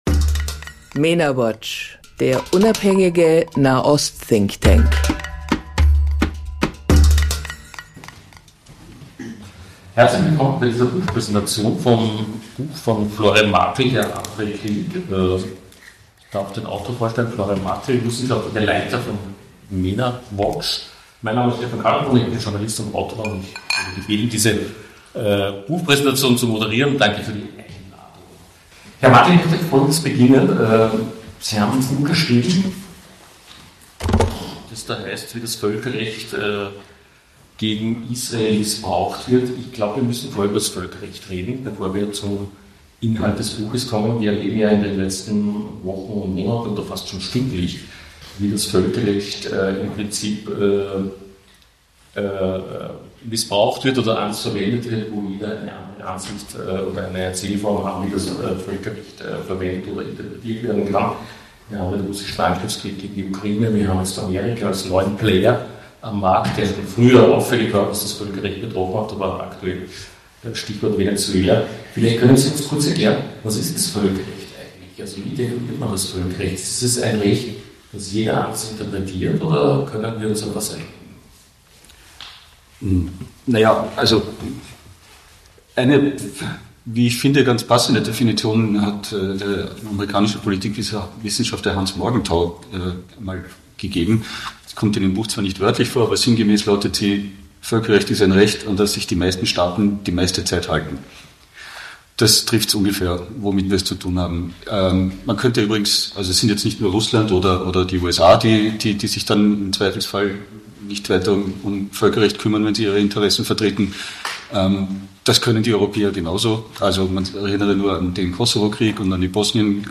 Völkerrecht als politisches Instrument: Buchpräsentation